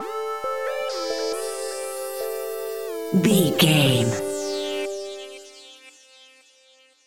Epic / Action
Fast paced
Mixolydian
aggressive
powerful
dark
funky
groovy
futuristic
energetic
drum machine
synthesiser
breakbeat
synth leads
synth bass